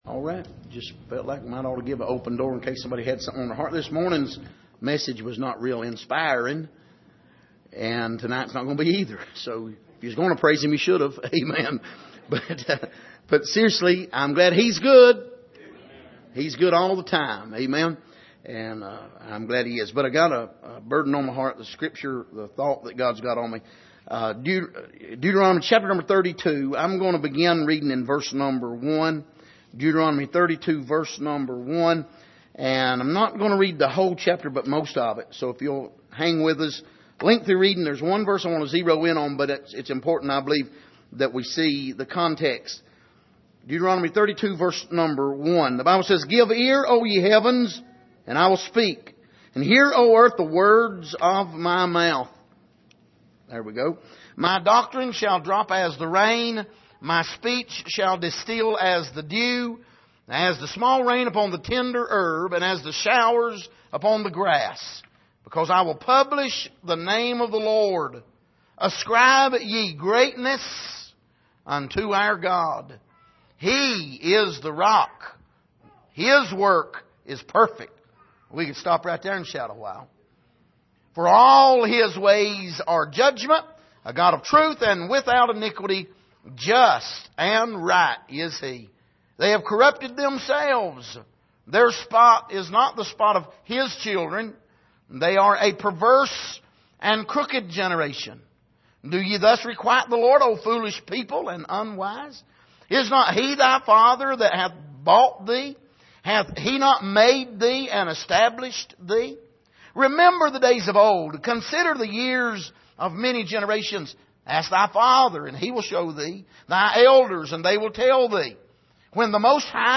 Passage: Deuteronomy 32:1-29 Service: Sunday Evening